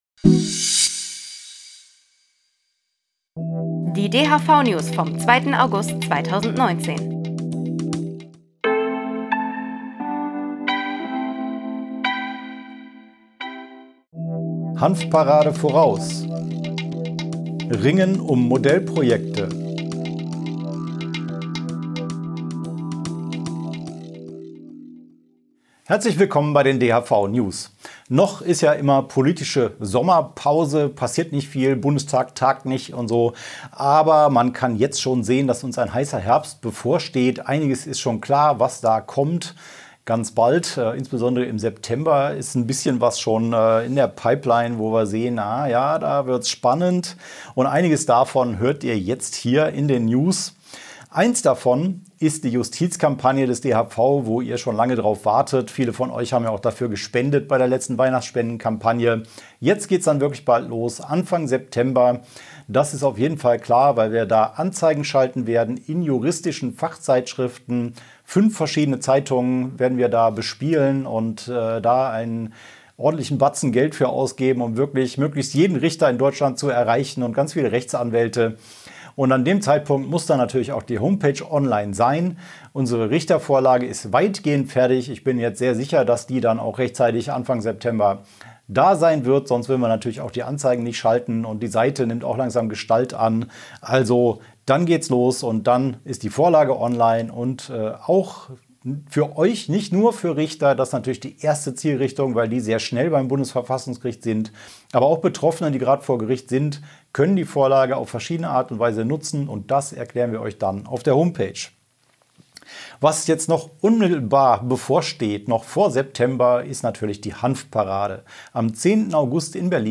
DHV-Video-News #212 Die Hanfverband-Videonews vom 26.07.2019 Die Tonspur der Sendung steht als Audio-Podcast am Ende dieser Nachricht zum downloaden oder direkt hören zur Verfügung.